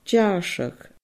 deàrrsadh /dʲaːRsəɣ/